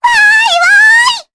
Luna-Vox_Happy4_jp.wav